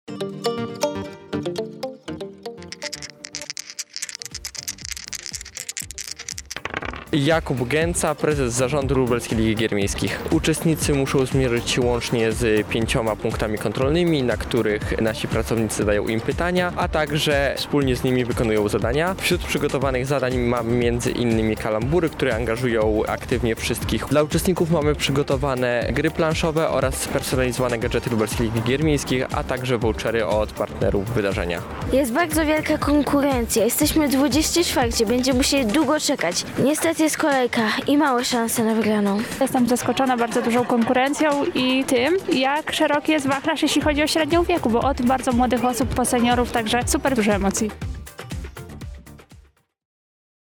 Więcej na temat wydarzenia mówią jego organizatorzy oraz sami gracze: